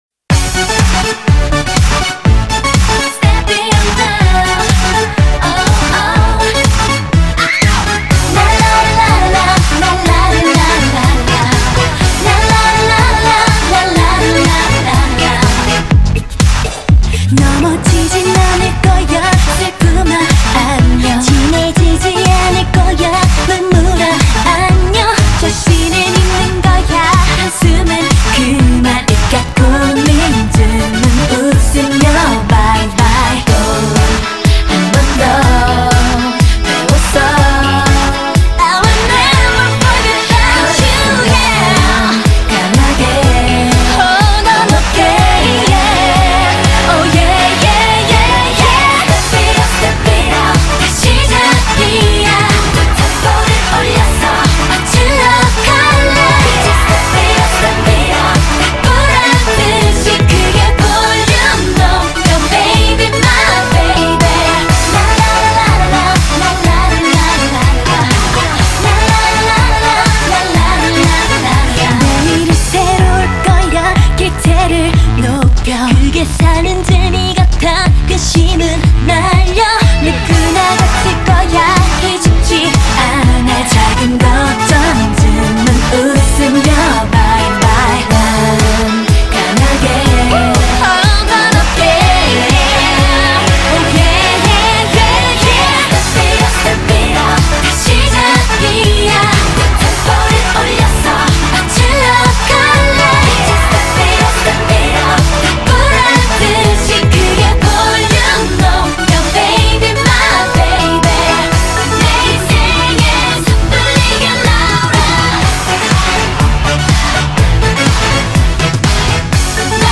BPM61-123
Audio QualityCut From Video